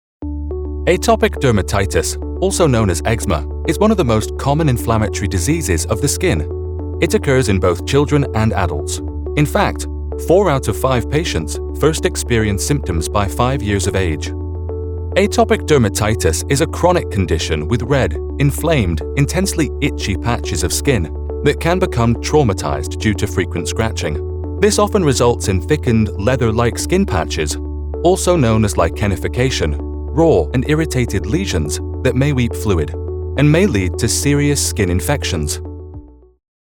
His voice is warm, assured, friendly, and authentic.
international english
NARRATION 😎